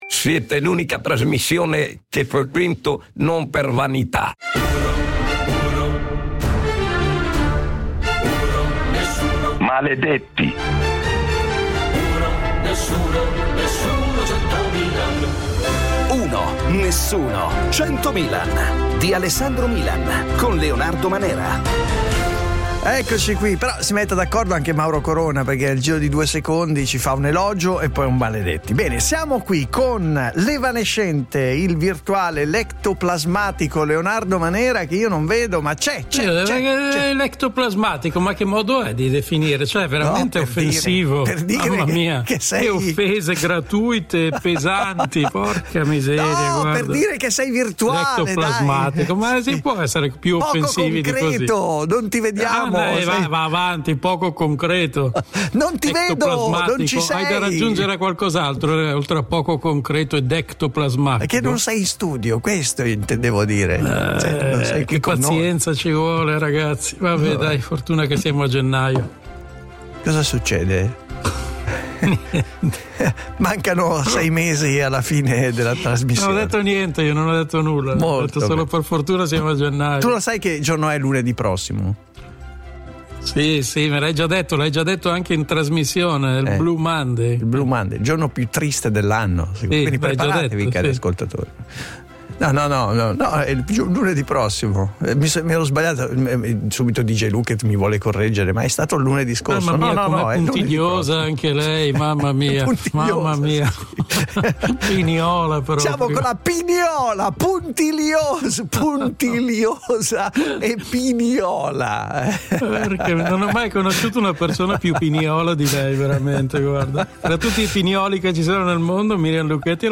Un morning show sui fatti e i temi dell’attualità